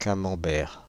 Camembert (/ˈkæməmbɛər/ KAM-əm-bair, UK also /-mɒm-/ -om-, French: [kamɑ̃bɛʁ]
Fr-Paris--Camembert.ogg.mp3